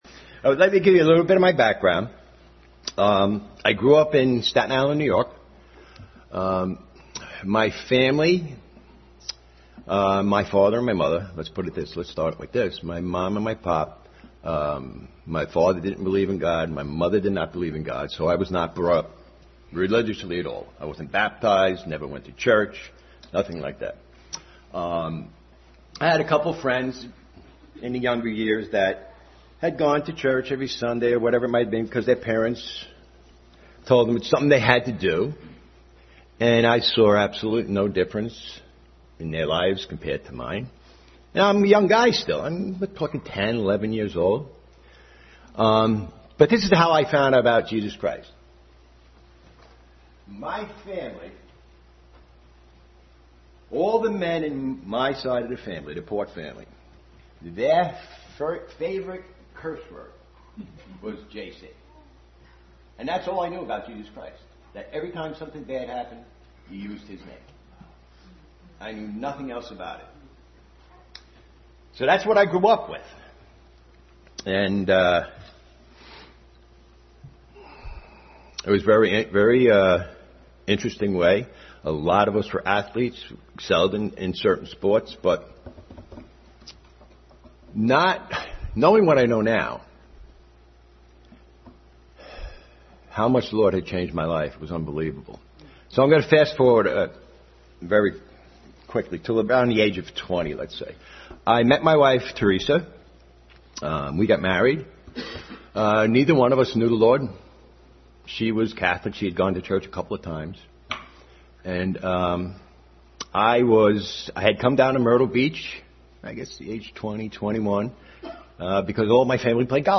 Testimony.